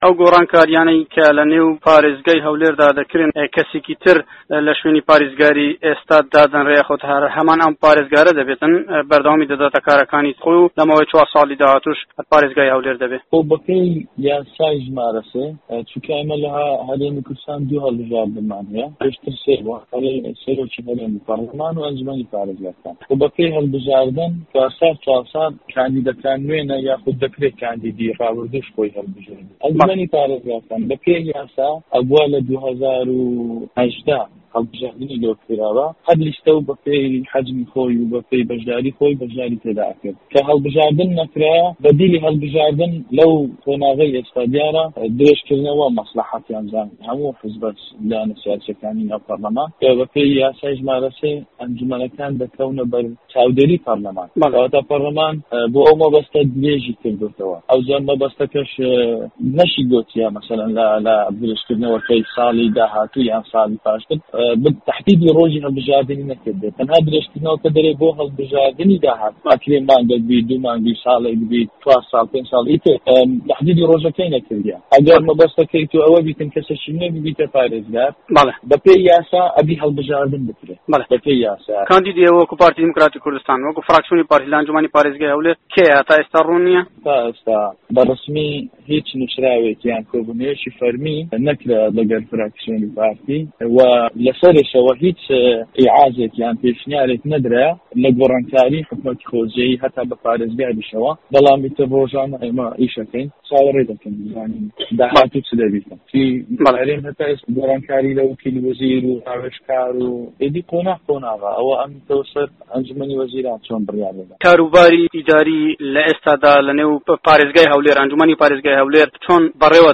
سەبارەت بە گۆڕانکارییە خوجێییەکان و هەڵبژاردنی ئەنجومەنی پارێزگاکان و ئیش و کارەکانی ئەنجومەنی پارێزگای هەولێر و دانانی پارێزگارێک ، کەنعان خەیلانی سەرۆکی فڕاکسیۆنی پارتی دیموکراتی کوردستان لە ئەنجومەنی پارێزگای هەولێر لە چاوپێکەوتنێکدا لەگەڵ دەنگی ئەمەریکا دەڵێت بە پێی یاسا دەبێت هەڵبژاردن بکرێت ، گۆڕانکاری ئیداری بەڕێوەنە و ئەو دوو مادەیەش کە پەرلەمان ئیشی لەسەرکردووە مادەی درێژکردنەوە تا هەڵبژاردنی داهاتوو و هی ئەنجومەنی پارێزگاش کە پارێزگار ئەندامی ئەنجومەن بێت ئێستا دەکرێت پارێزگارێک لە دەرەوەی ئەنجومەن هەڵببژێرێت و دەشکرێ ئەندامی ئەنجومەن بێت و ئێستاش دەست بەکارین وکاری خومان دەکەین ڕۆژانە و ئیتر چاوەڕی دەکەین بزانین حکومەتی هەرێم و هەموو لایەنە سیاسیەکان هەنگاو دەنێن بەرەو گۆڕانکاری.